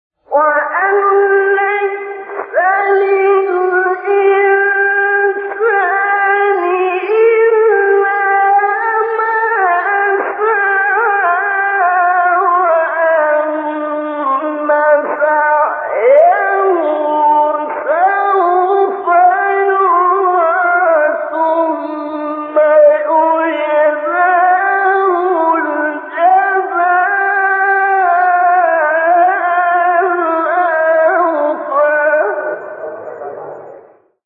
سوره : نجم آیه: 39-41 استاد : ابوالعینین شعیشع مقام : بیات قبلی بعدی